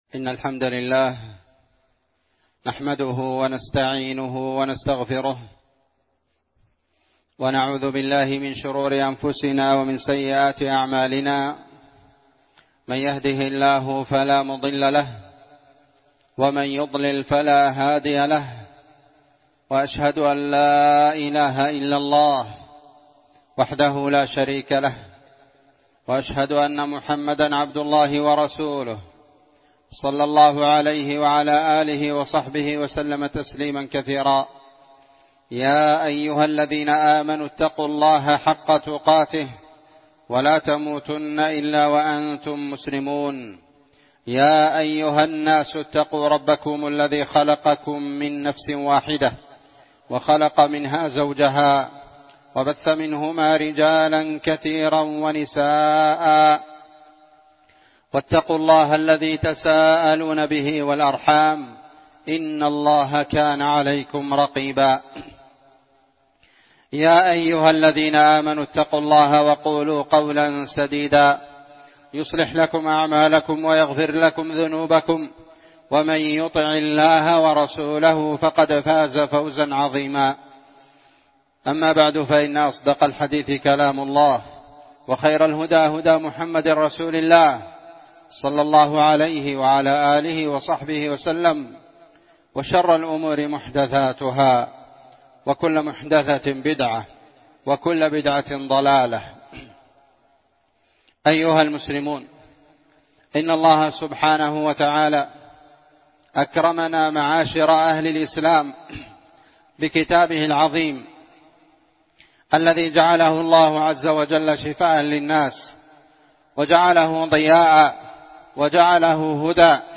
خطبة جمعة
إندونيسيا- جزيرة سولاويسي- مدينة بوني- قرية تيرونج- مسجد الإخلاص